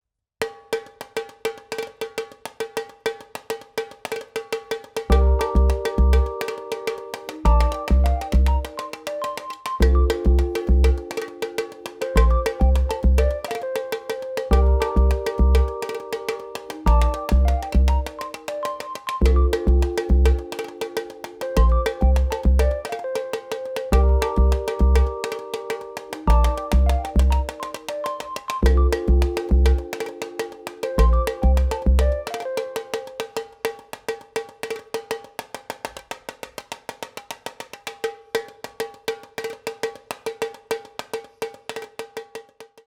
Tamborims are played with a plastic stick while one hand changes the drum´s pitch. It has a bright, loud, and cutting sound.